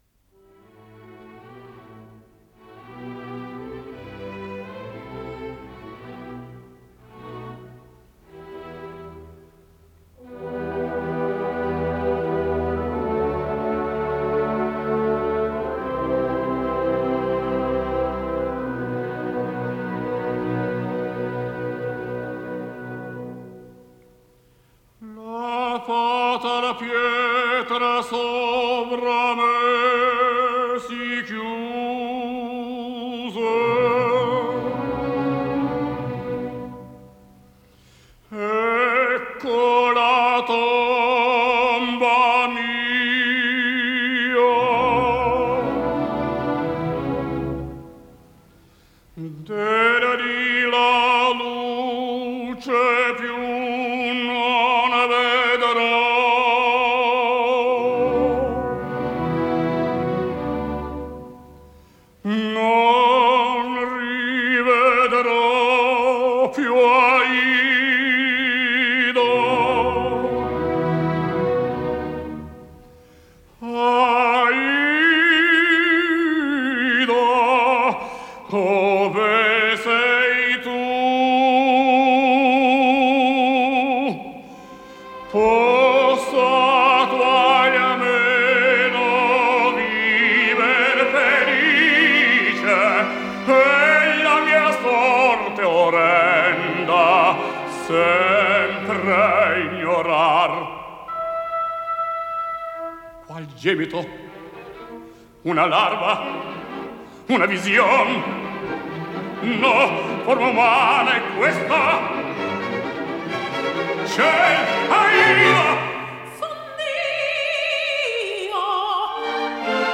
Исполнитель: Солисты, хор и оркестр Софийской народной оперы